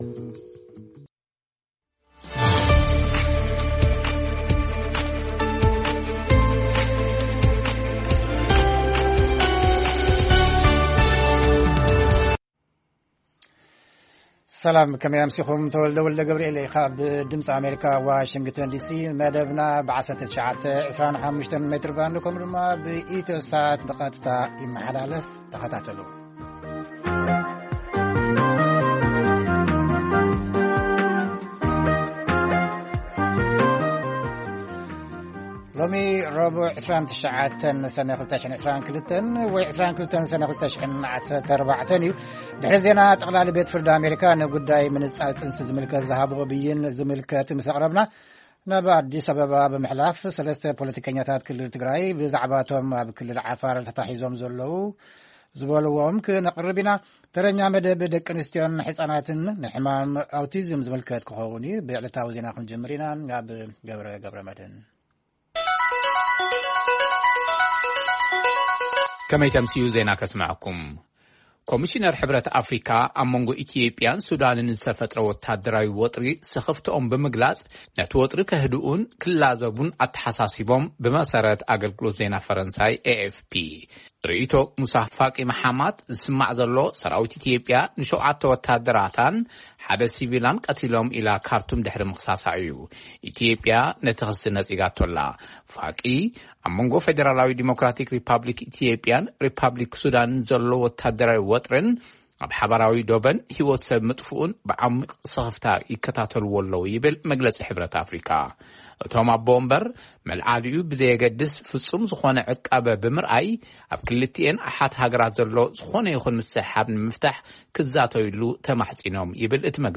ዜና
ቃለ መጠይቕ